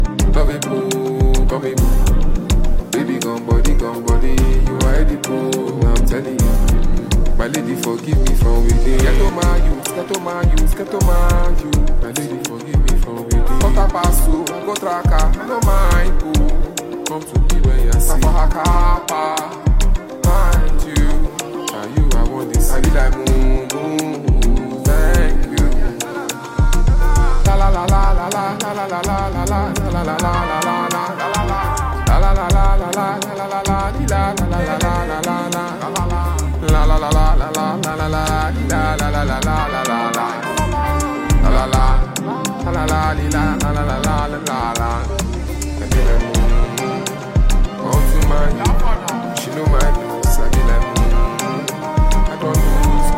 a versatile Nigerian rap artist and musician
ferocious song